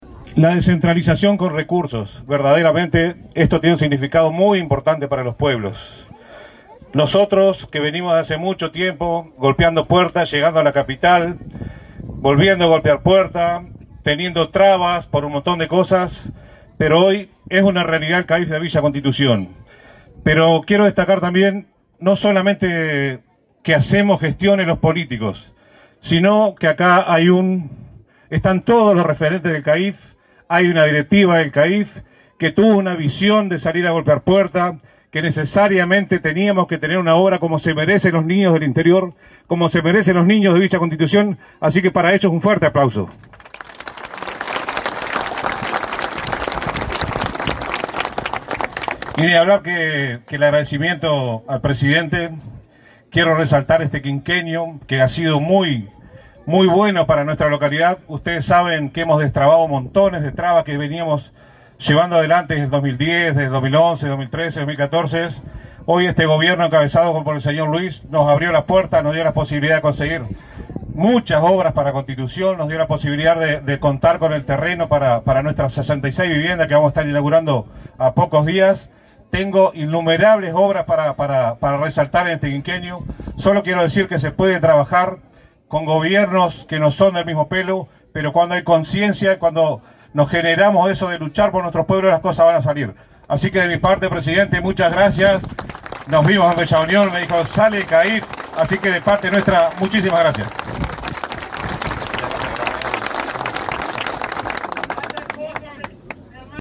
Palabras del alcalde de Villa Constitución, Carlos Souto
Palabras del alcalde de Villa Constitución, Carlos Souto 05/09/2024 Compartir Facebook X Copiar enlace WhatsApp LinkedIn Con la participación del presidente de la República, Luis Lacalle Pou, se realizó el acto de inauguración, este 5 de setiembre, de un centro de atención a la infancia y la familia en la localidad de Villa Constitución, en Salto. En el evento disertó el alcalde Carlos Souto.